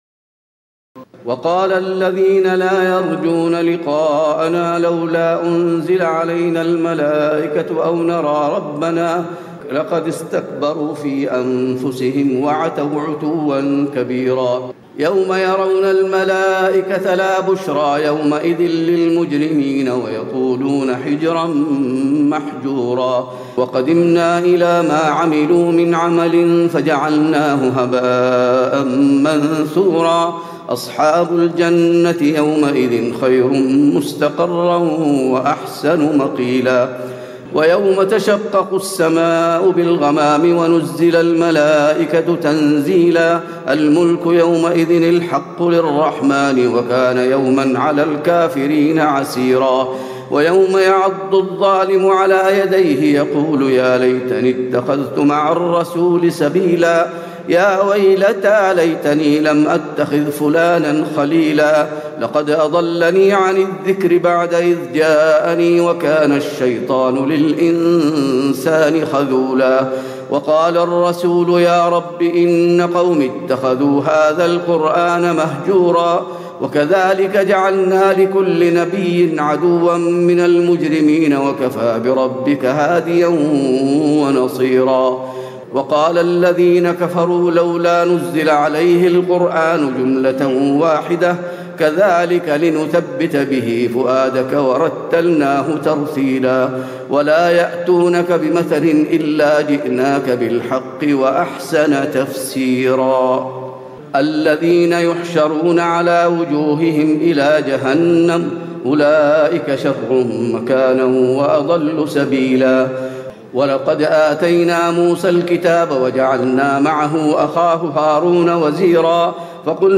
تراويح الليلة الثامنة عشر رمضان 1436هـ من سورة الفرقان (21-77) Taraweeh 18 st night Ramadan 1436H from Surah Al-Furqaan > تراويح الحرم النبوي عام 1436 🕌 > التراويح - تلاوات الحرمين